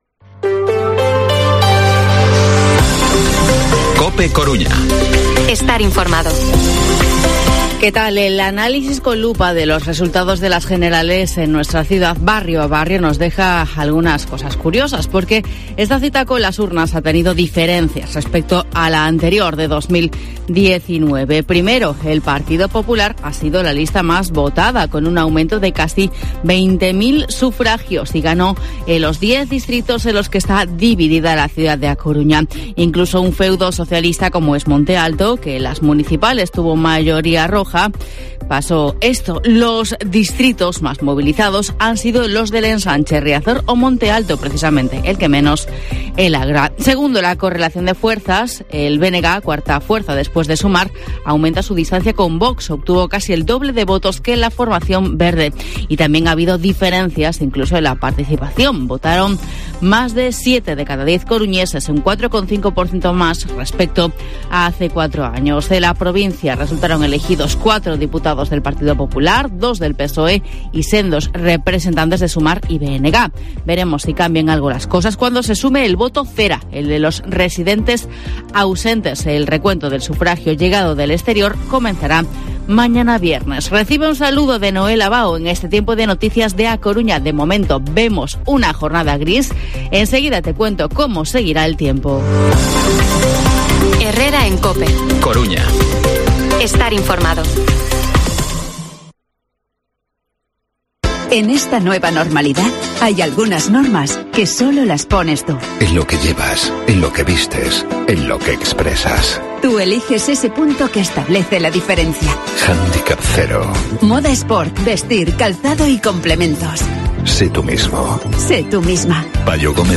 Informativo Herrera en COPE Coruña jueves, 27 de julio de 2023 8:24-8:29